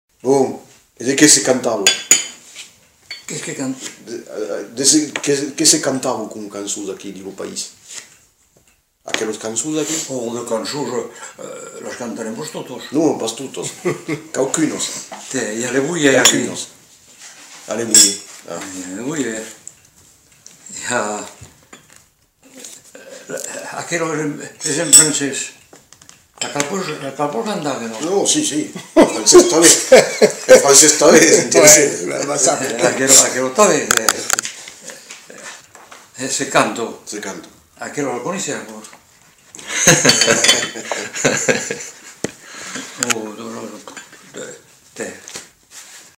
Aire culturelle : Lauragais
Lieu : Le Faget
Genre : témoignage thématique